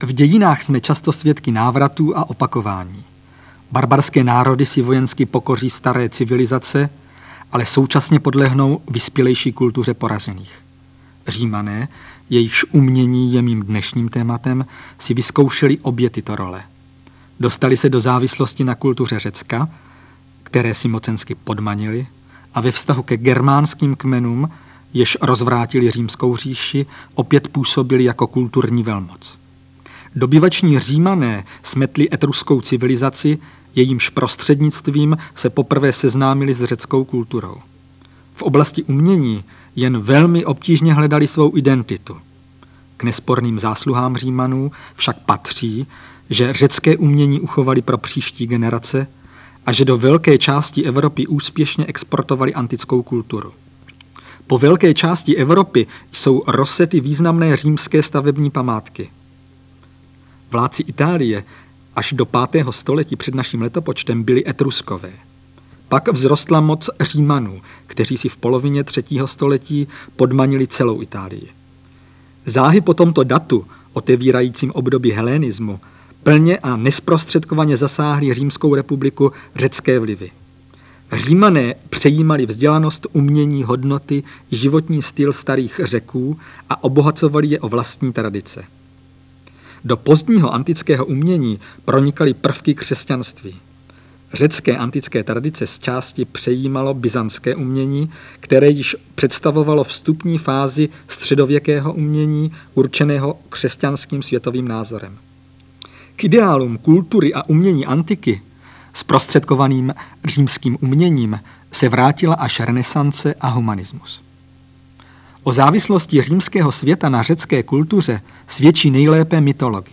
Přednášky